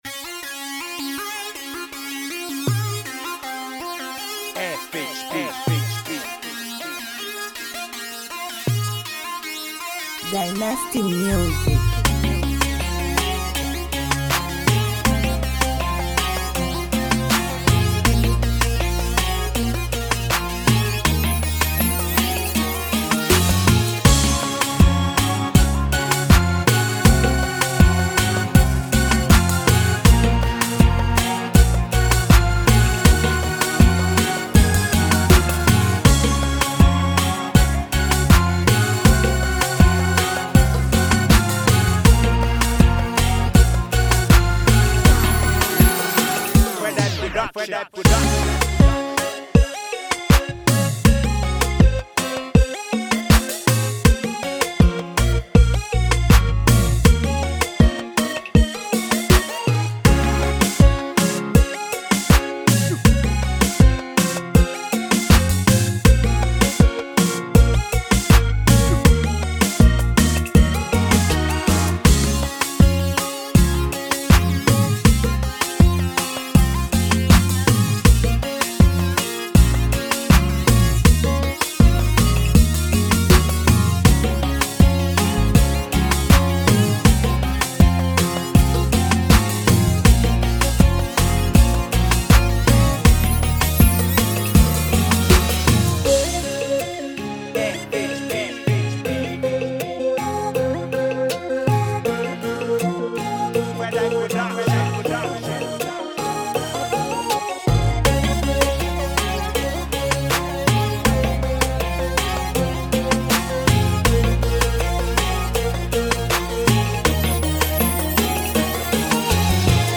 INSTRUMENTAL Download 9301 downloads6.32 MB “
Genre: HYPE!Beatz.